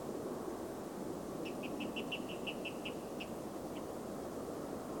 Water Rail (Rallus aquaticus)
A series of slightly grunted chip notes, often slowing down (becoming more widely spaced) towards the end. The number of chips and their spacing is variable.
Water Rail chips
clip_wa_pips.mp3